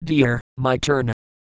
Worms speechbanks
yessir.wav